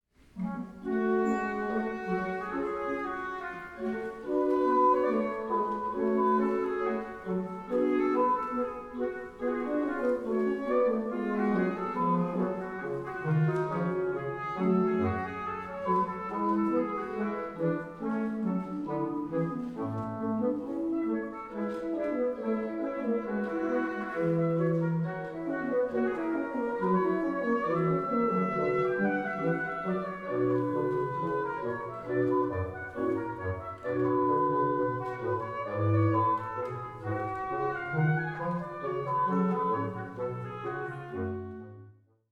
Ausschnitt aus der Arie:
Livemitschnitt